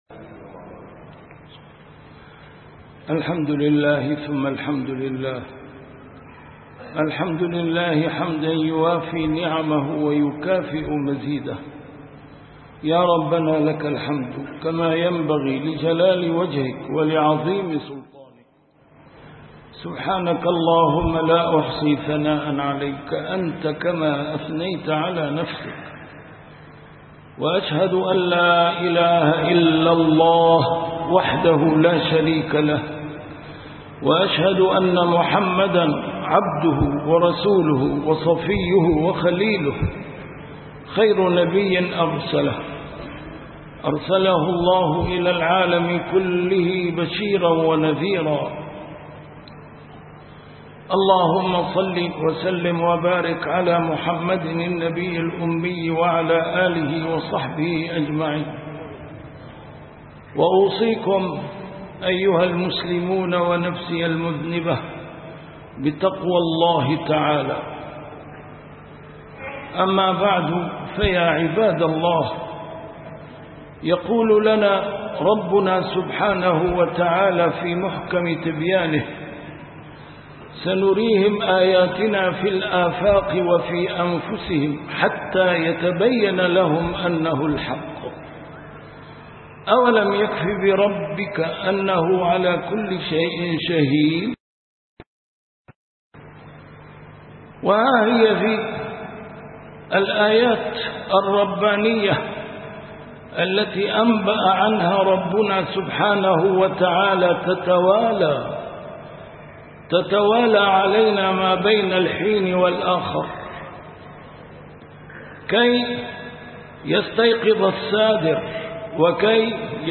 A MARTYR SCHOLAR: IMAM MUHAMMAD SAEED RAMADAN AL-BOUTI - الخطب - سكرة الموت تحيق بالنظام الرأسمالي